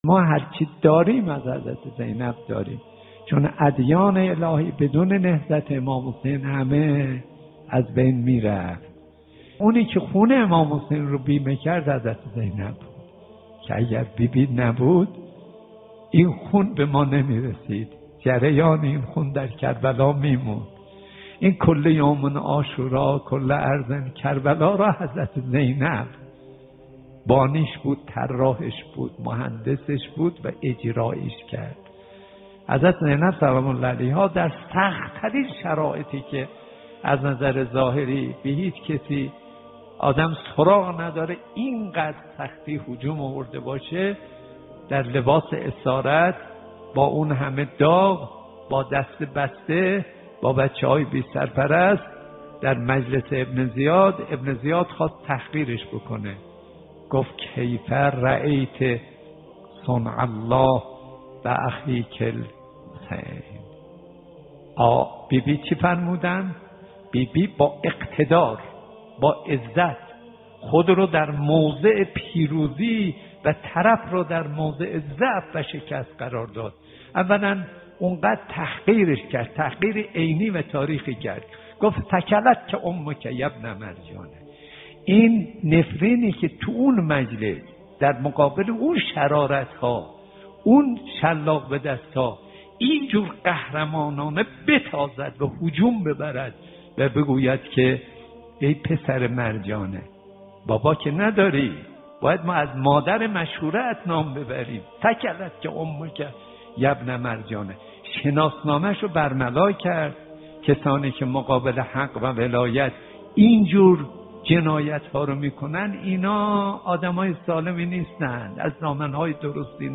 به مناسبت سالروز وفات حضرت زینب (س) بخشی از سخنرانی حجت‌الاسلام والمسلمین کاظم صدیقی با عنوان «حضرت زینب (س)؛ اسوه صبر» تقدیم مخاطبان گرامی ایکنا می‌شود.